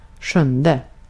Ääntäminen
IPA: /ˈɧɵnˌdɛ/